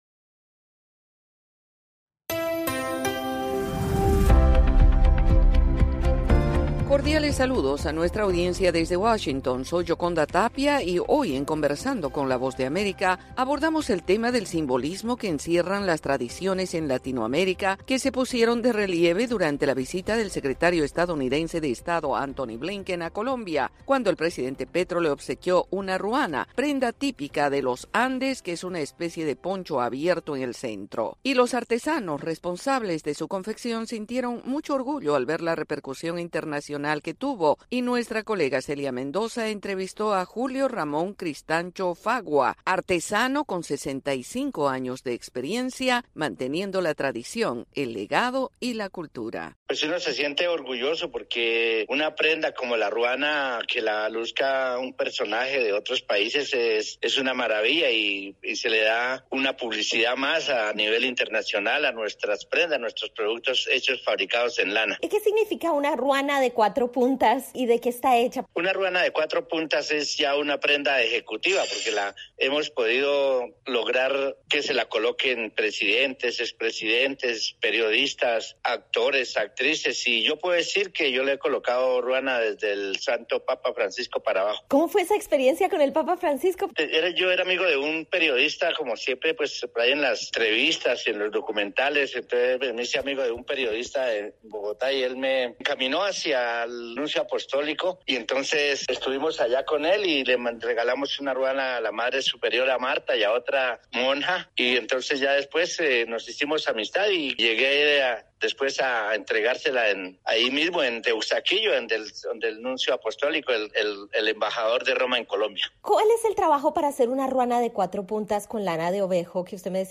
Conversamos con el artesano colombiano